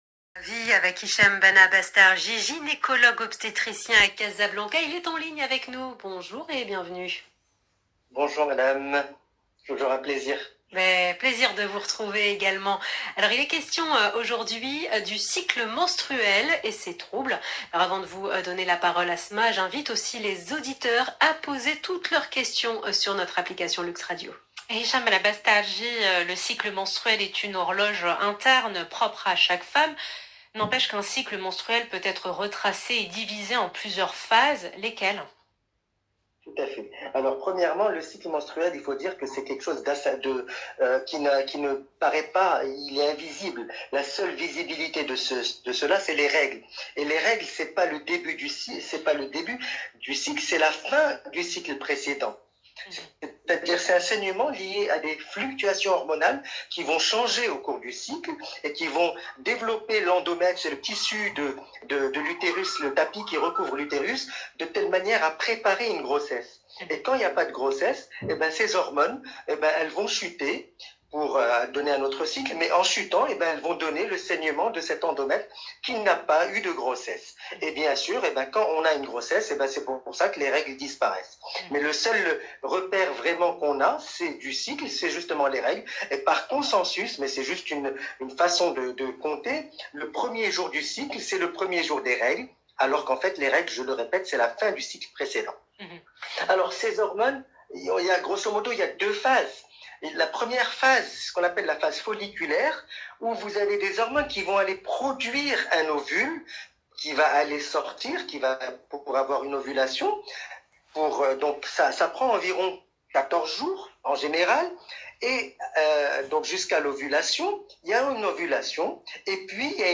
J’essaie de répondre à toutes ces questions dans cette interview dans l’Heure Essentielle sur Luxe Radio du 02 juin 2020.